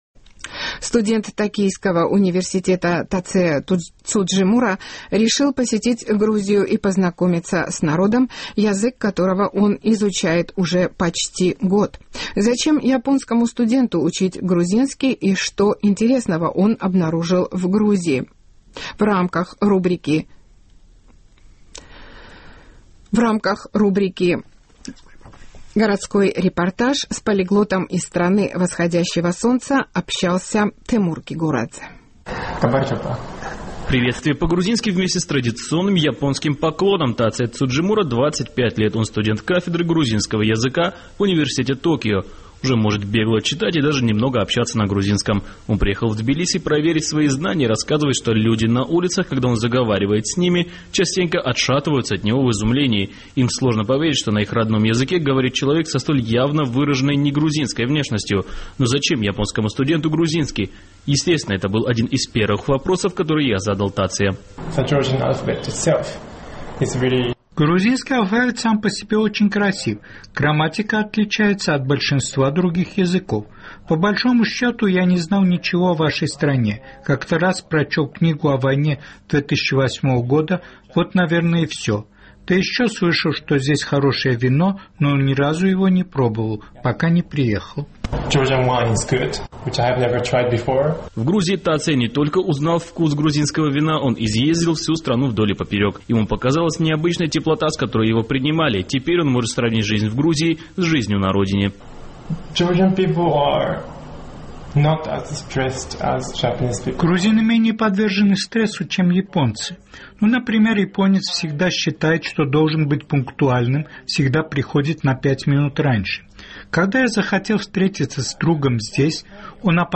"Гамарджоба" с японским акцентом